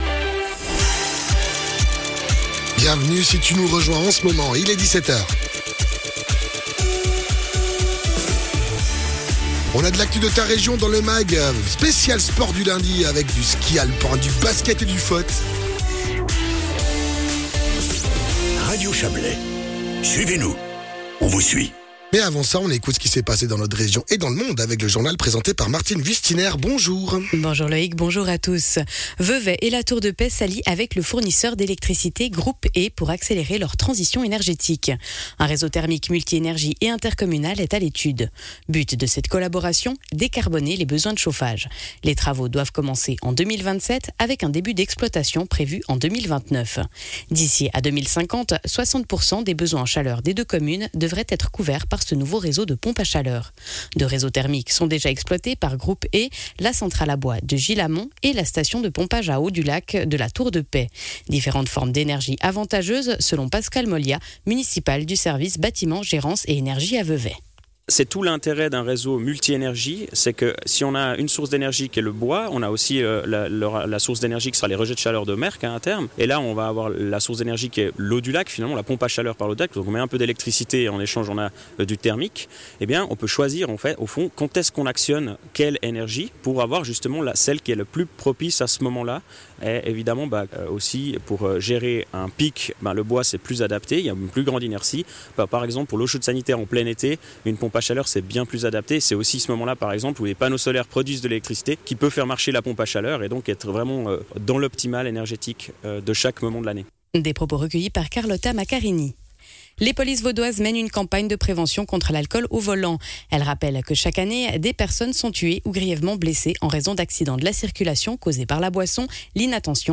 Les infos de 17h00 du 02.12.2024